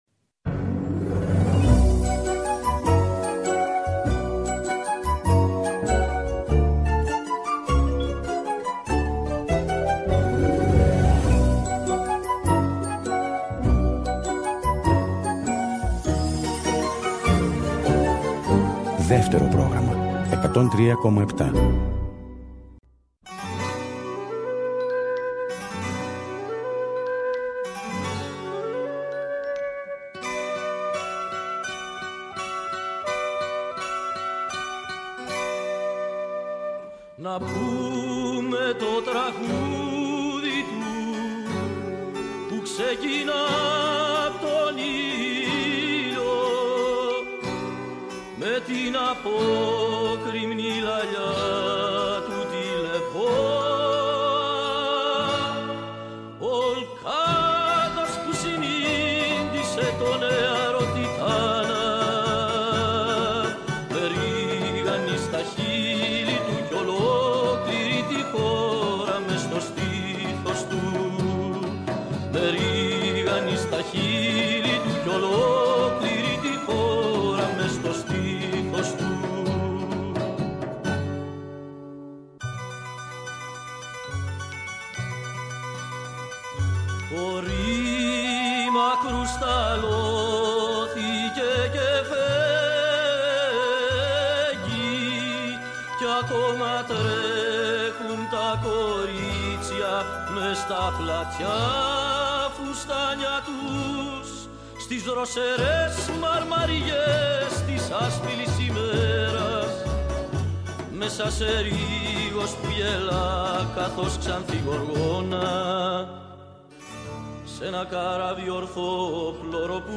Σήμερα στη ραδιοφωνική μας συντροφιά βρέθηκε η Όλια Λαζαρίδου. Συζητήσαμε ποιοι είναι οι «σαλοί» της εποχής μας, πότε γινόμαστε εραστές του παράλογου και πού έχει…κατασκηνώσει η λογική.
Συνεντεύξεις